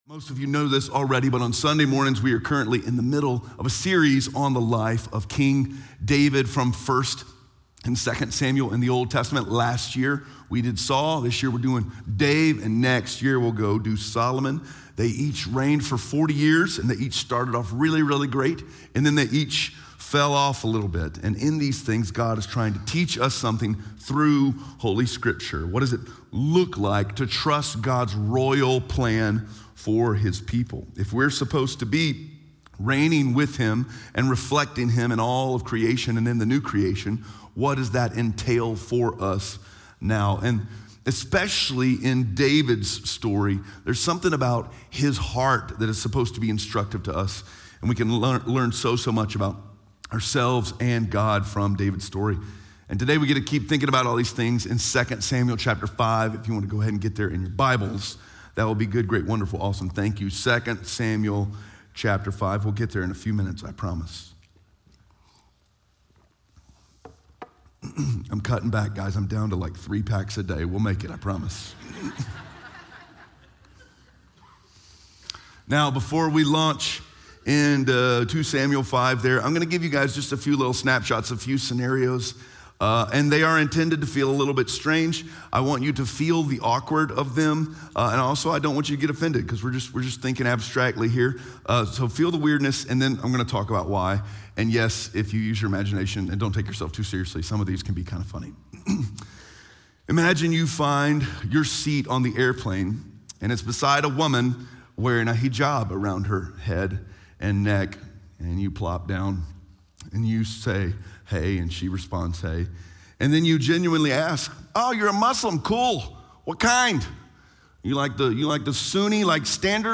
2 Samuel 5:1-10 Audio Sermon Notes (PDF) Ask a Question SERMON SUMMARY Politics and religion can both feel extra fragile because both are making claims on who has the right to rule the world.